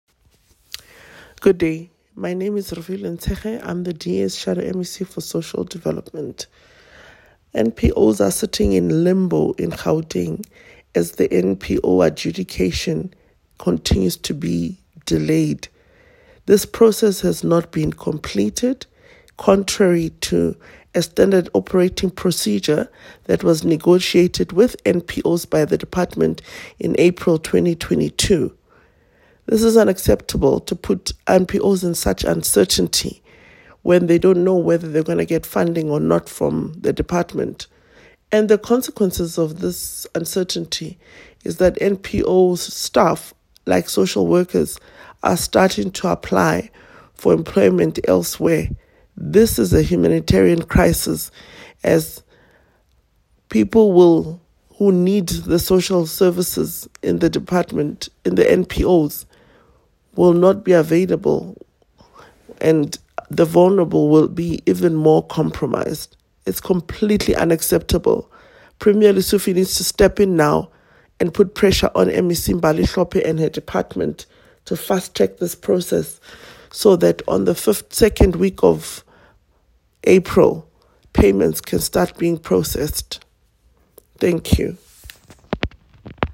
soundbite by Refiloe Nt’sekhe MPL
Refiloe-Ntsekhe-voice-recording.mp3